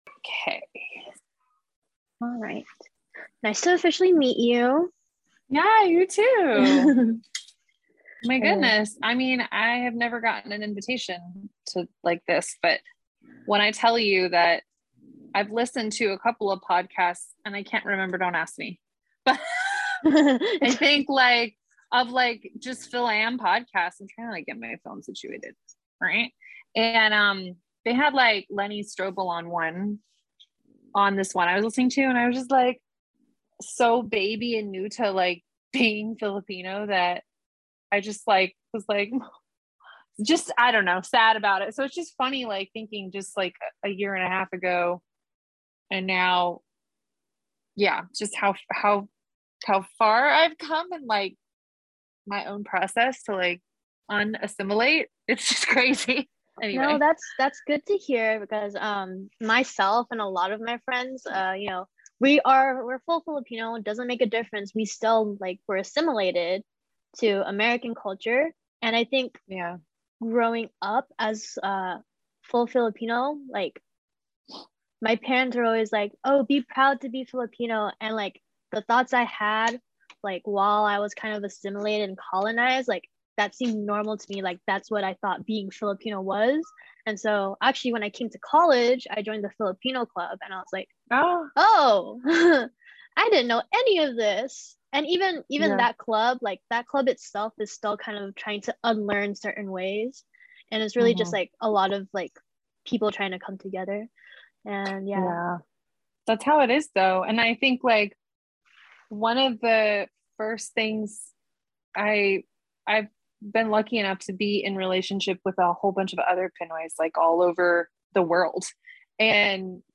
Was originally on zoom but video and audio could not line up correctly.